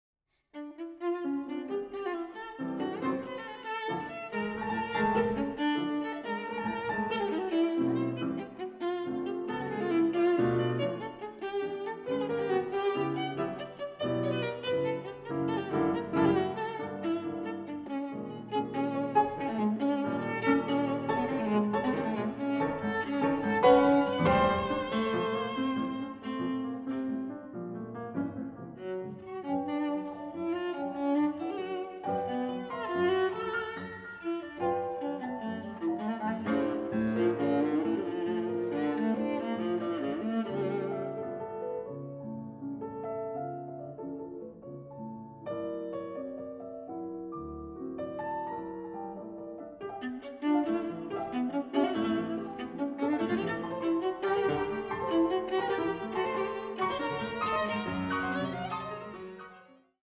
Viola
Klavier